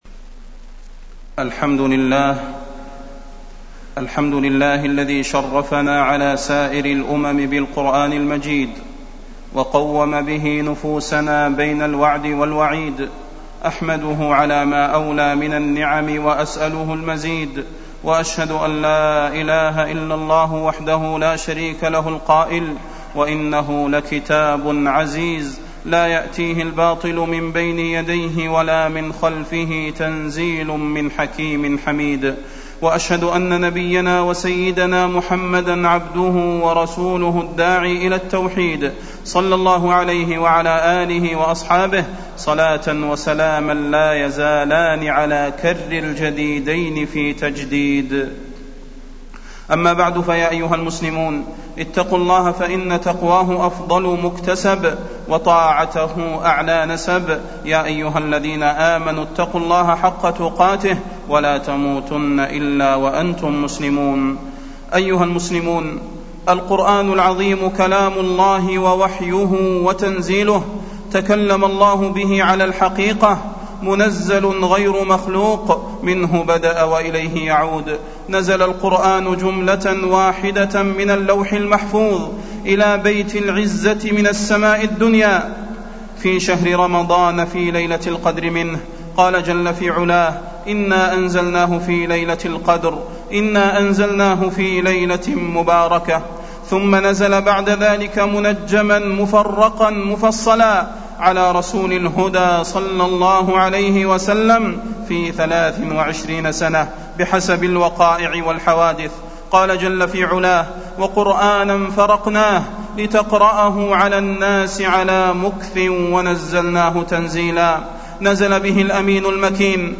تاريخ النشر ١٦ رمضان ١٤٢٨ هـ المكان: المسجد النبوي الشيخ: فضيلة الشيخ د. صلاح بن محمد البدير فضيلة الشيخ د. صلاح بن محمد البدير مواعظ القرآن